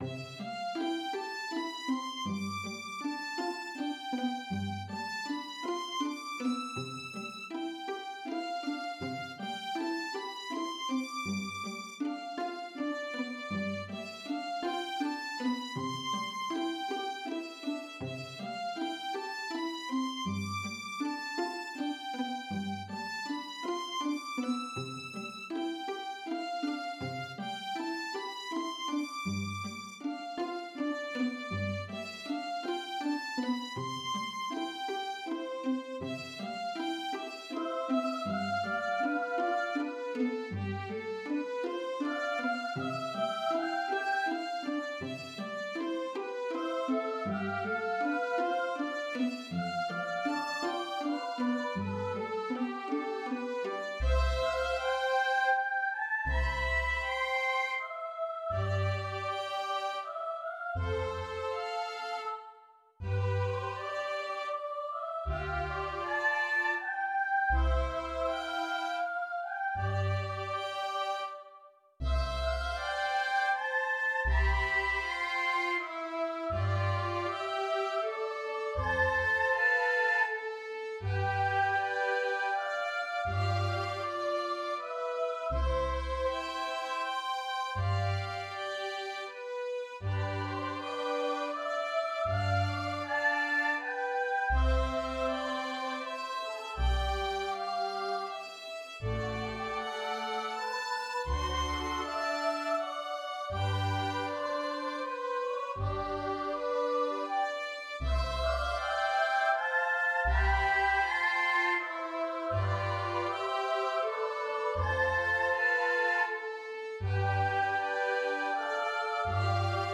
Komolyzene